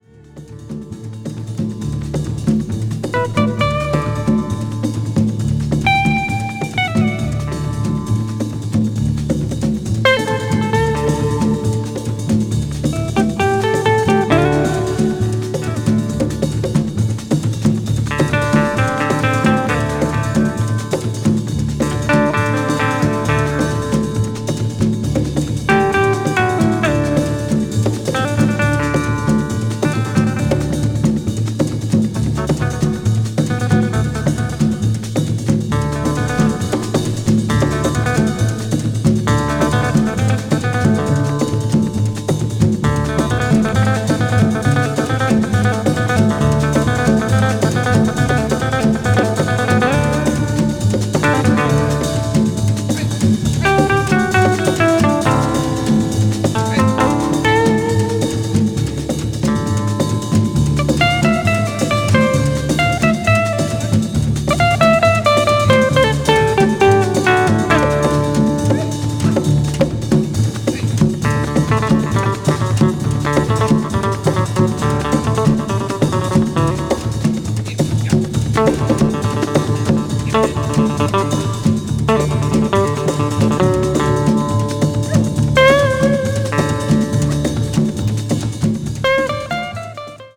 Mono. coated gatefold sleeve.